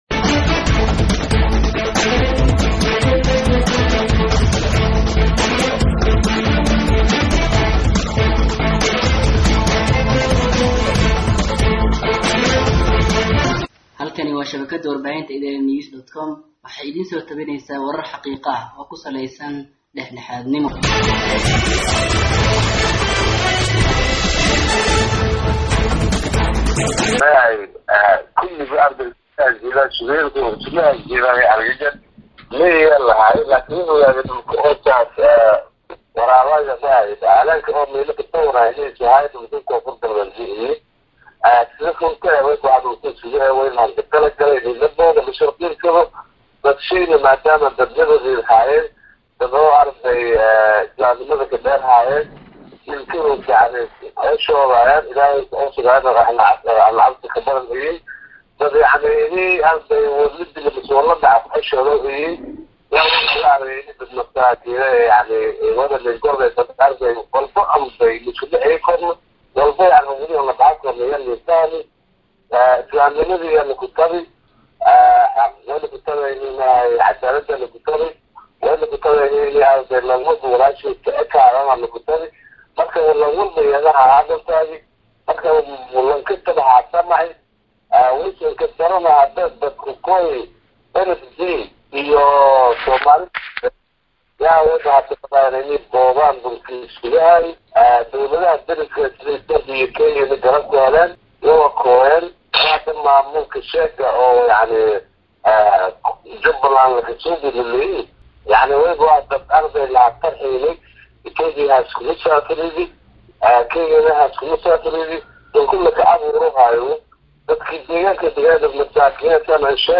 Dhageyso Wareysi: Xildhibaan Afaraalle Oo U Jawaabey Hogaamiyaha Maamulka Jubba